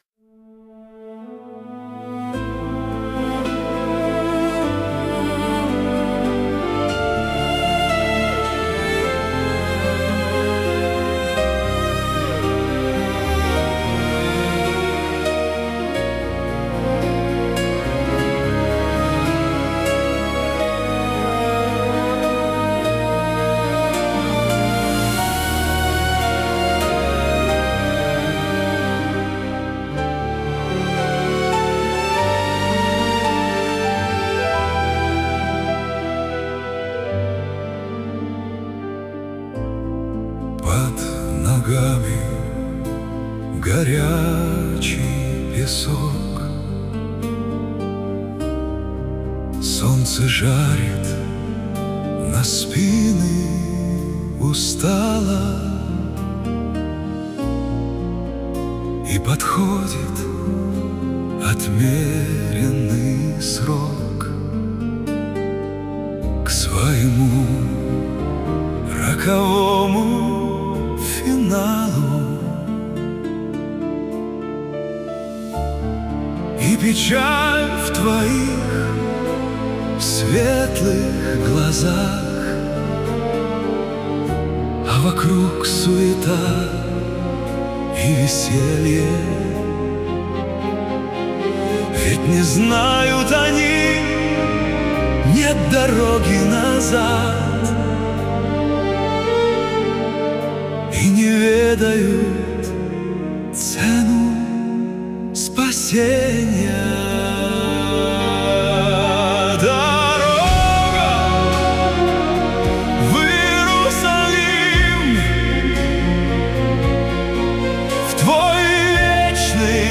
Зацепили стихи, и случилась первая попытка в виде зарисовки.